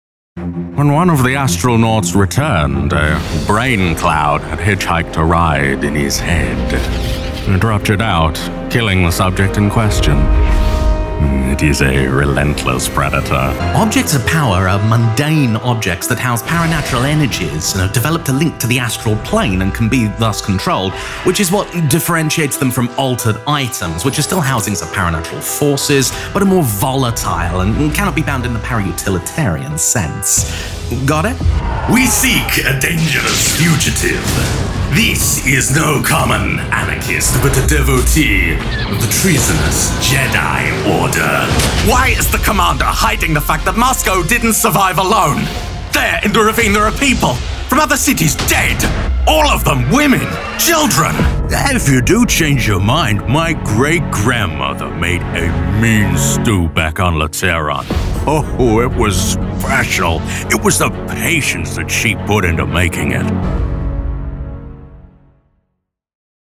Videogames
Plenty of different studios have relied on me and my versatile vocal range to bring to life a diverse array of characters and creatures. I've been a shotgun-wielding woodpecker, a giant with its own complex language and creatures from Chinese folklore.
Demo